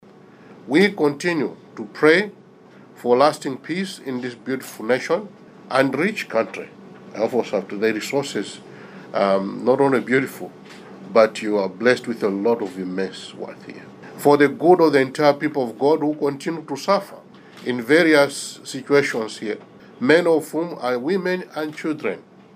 The Catholic prelates were speaking during the opening of a three-day meeting at the Sudan and South Sudan Bishops’ Conference in Juba.